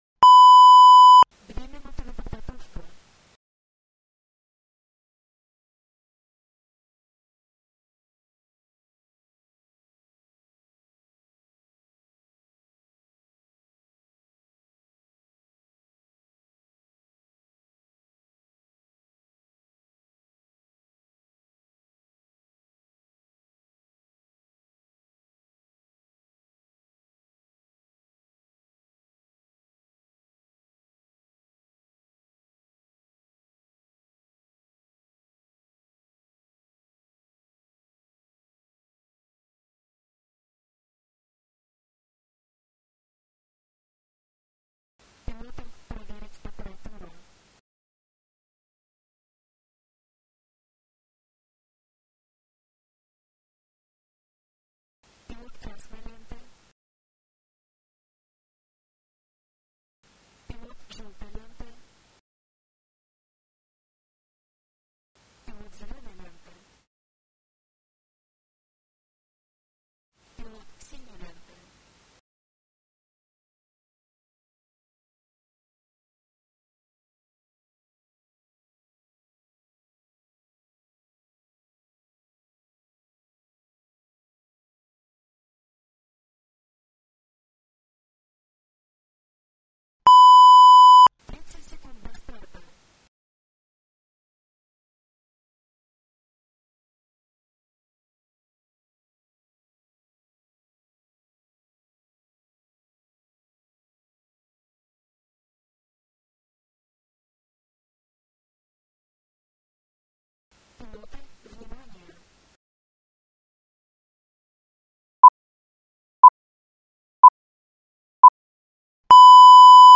Файл звукового сопровождения ведения старта (женский голос)